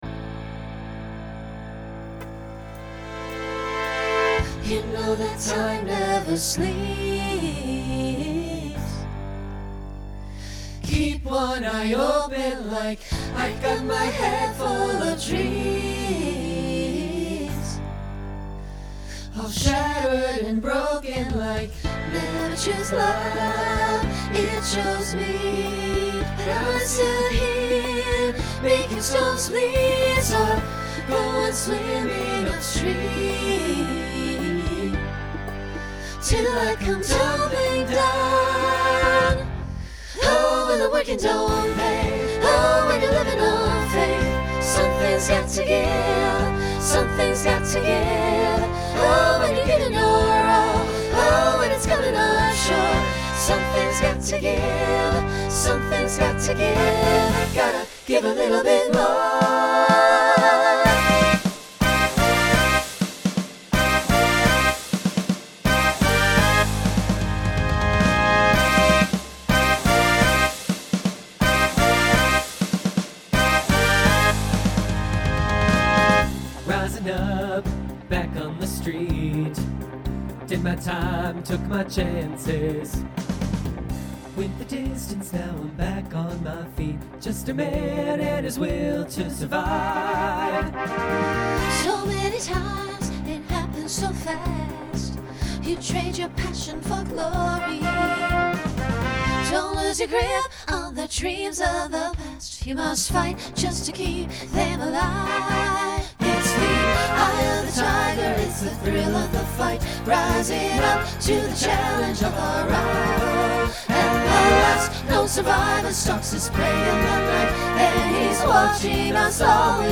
Pop/Dance , Rock
Costume Change Voicing Mixed